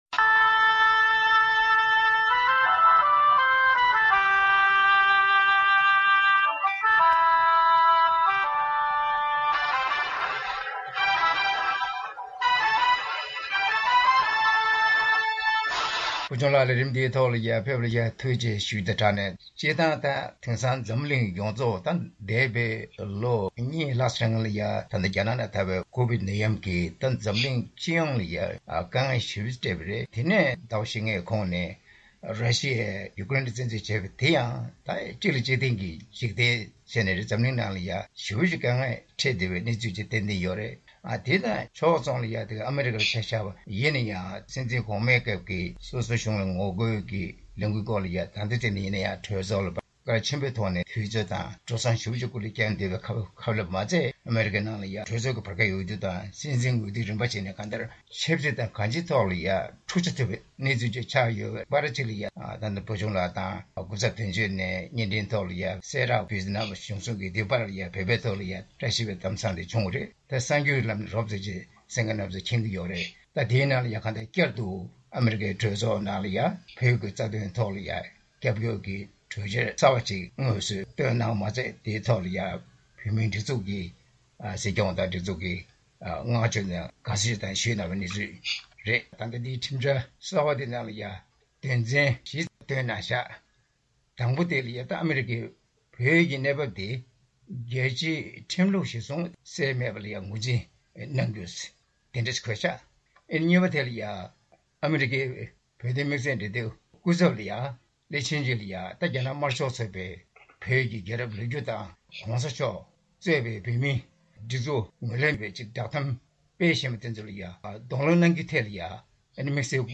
དཔྱད་གླེང་གནང་བའི་ལས་རིམ།